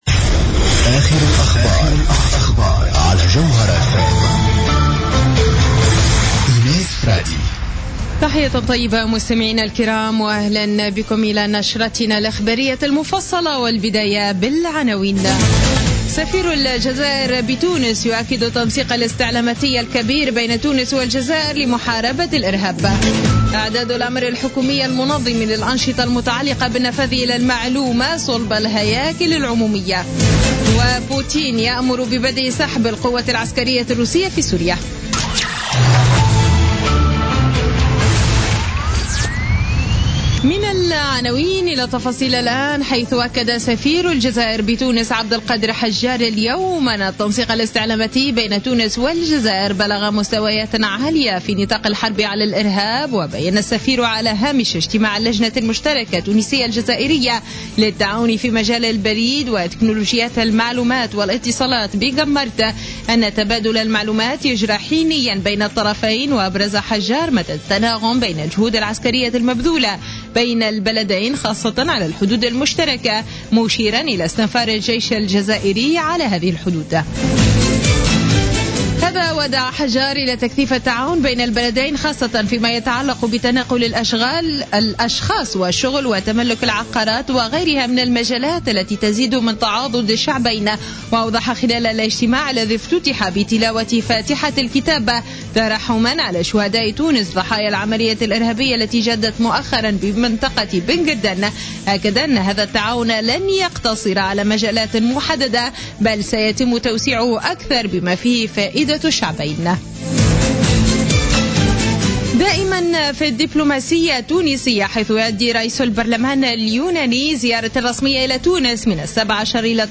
نشرة أخبار السابعة مساء ليوم الاثنين 14 مارس 2016